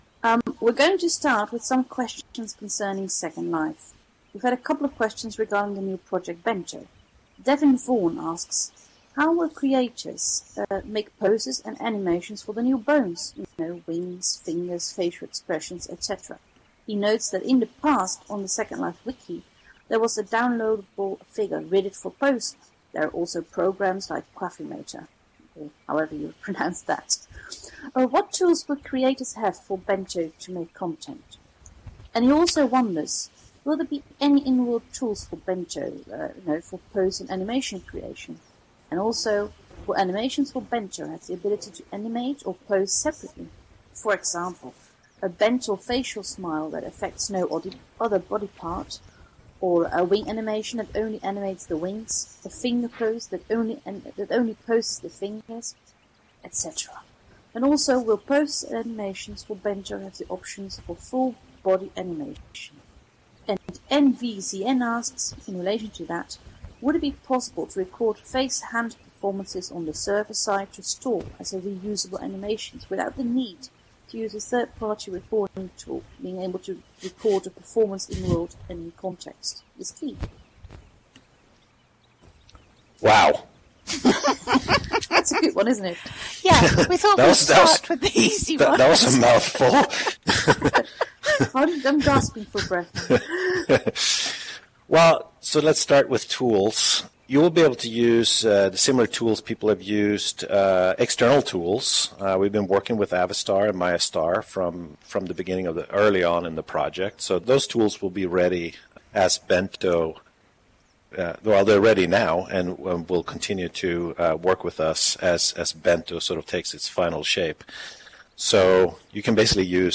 Each question and response is supplied with an accompanying audio extract.